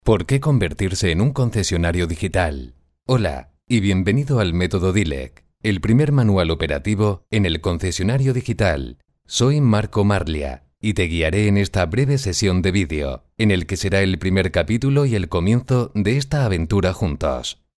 Voice persuasive, convincing, multiple records.
kastilisch
Sprechprobe: Sonstiges (Muttersprache):